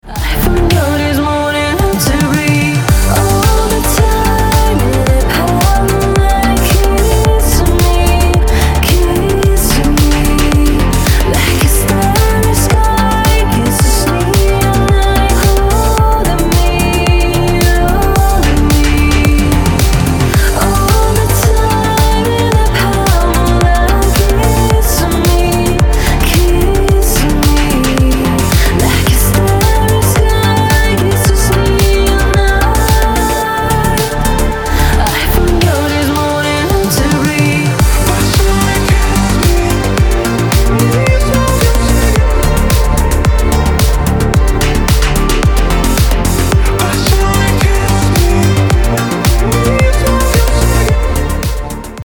• Качество: 320, Stereo
громкие
мощные
deep house
чувственные
красивый женский вокал
synthwave